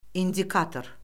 indən İzah indi İzah indi-indi İzah indicə İzah indicə-indicə İzah indidən İzah indiferent İzah indiferentlik İzah indik a tor İzah Səsləndir indikatris (riyaz.)